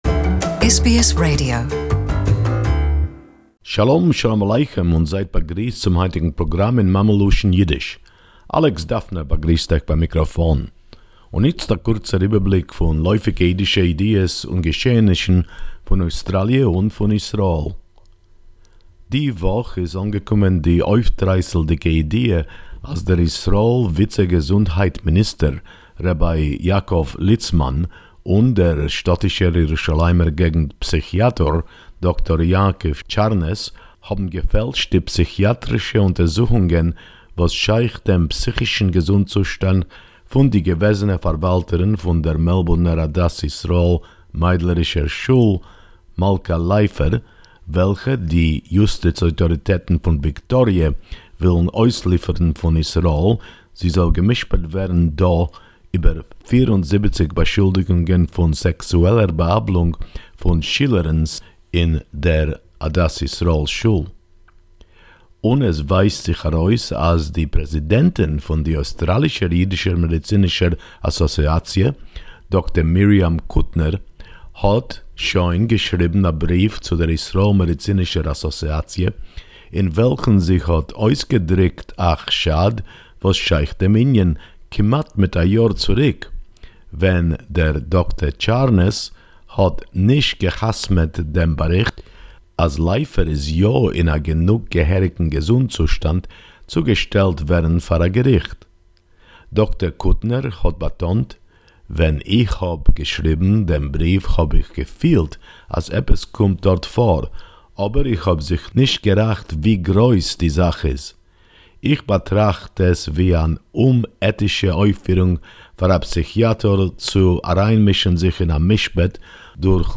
Weekly news report for our Yiddish listeners